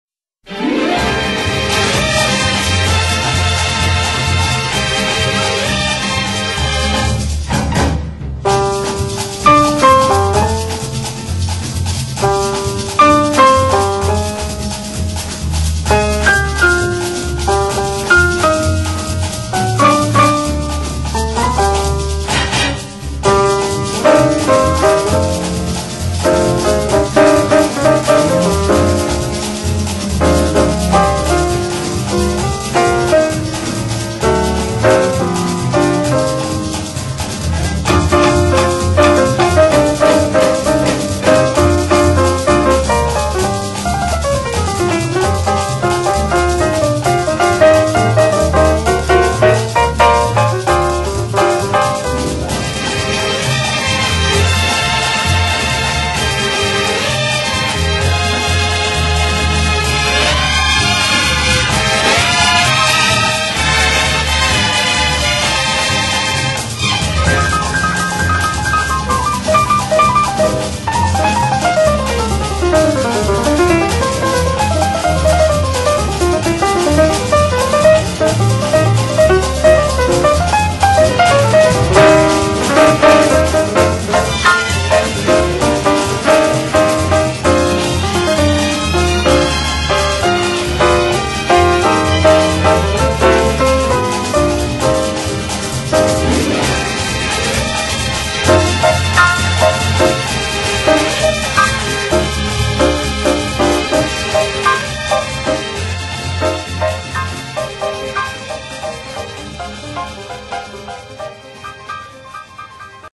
Blues And Jazz Para Ouvir: Clik na Musica.